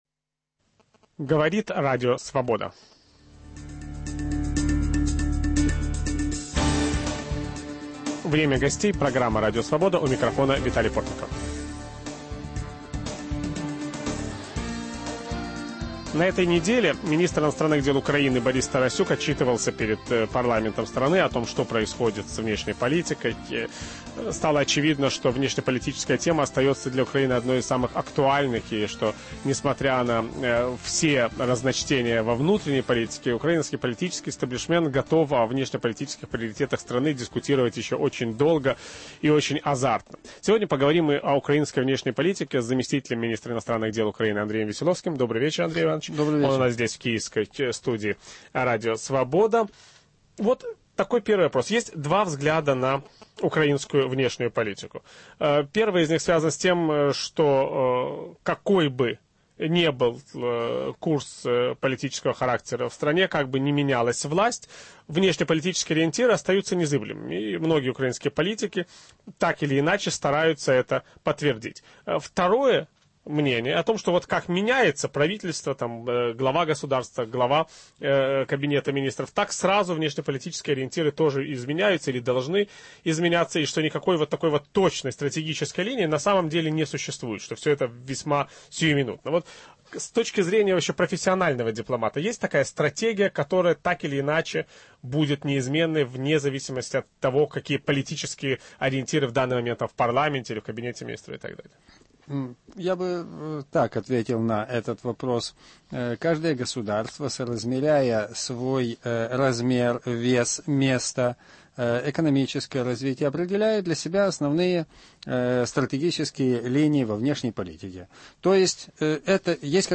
Об украинской внешней политике ведущий программы Виталий Портников беседует с заместителем министра иностранных дел Украины Андреем Веселовским.